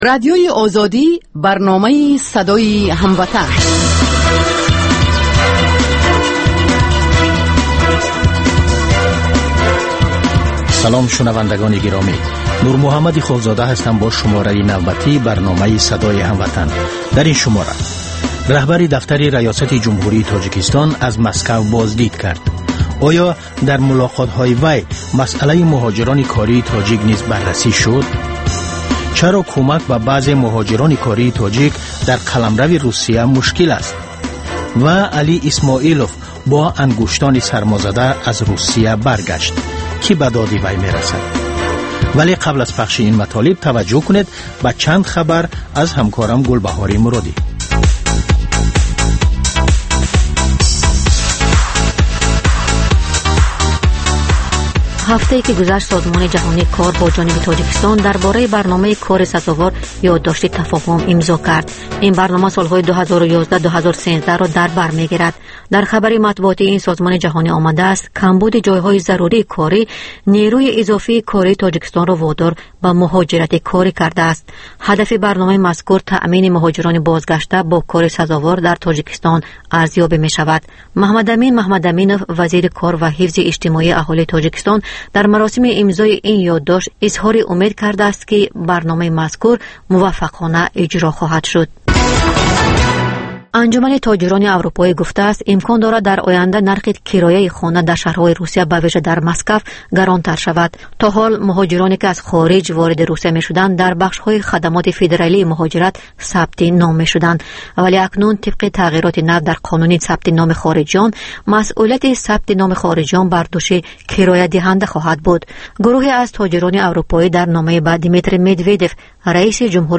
Баррасии рӯйдодҳои сиёсии Тоҷикистон, минтақа ва ҷаҳон дар гуфтугӯ бо таҳлилгарон.